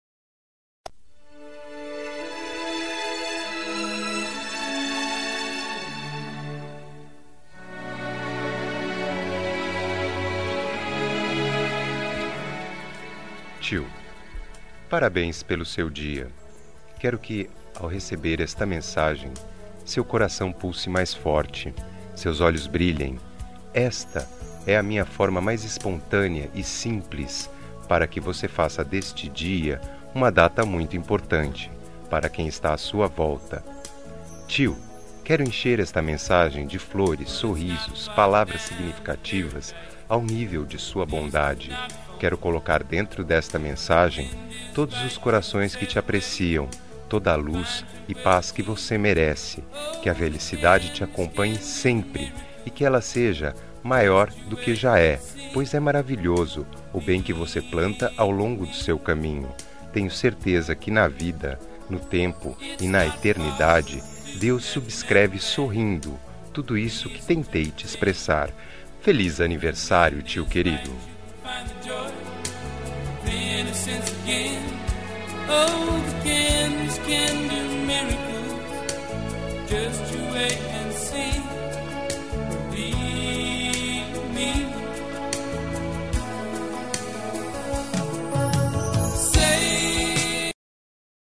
Aniversário de Tio – Voz Masculina – Cód: 916